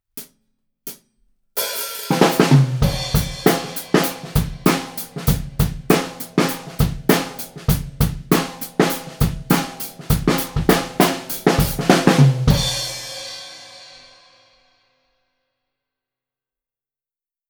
すべて、EQはしていません。
①　アンビエンス 約２ｍ
まずはじめに、ドラムから２ｍぐらい離したところにマイキングしました。
わりと、スッキリしていますね！
そして、高音がきれいに録れていますね！